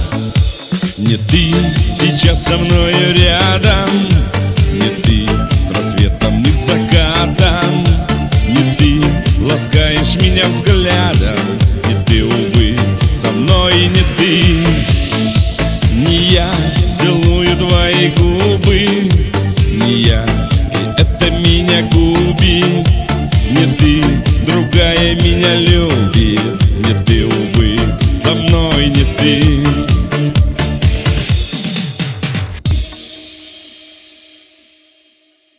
• Качество: 192, Stereo
грустные